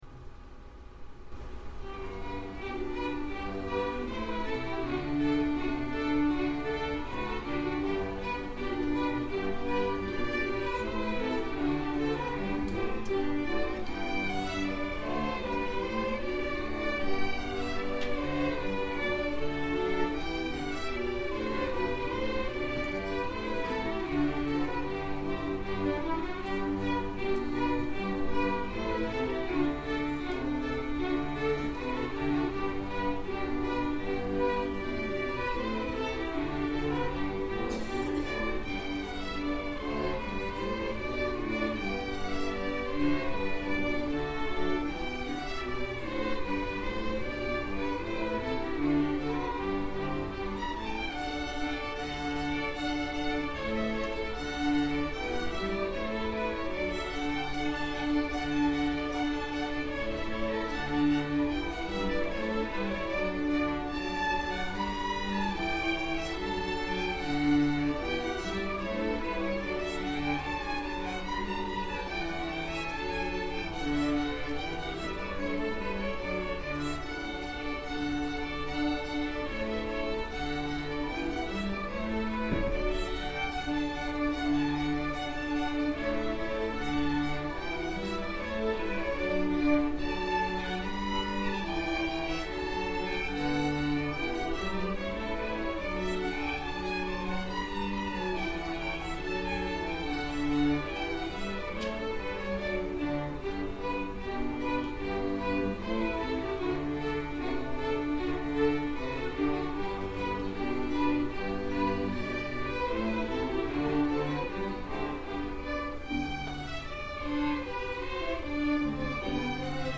Orchestra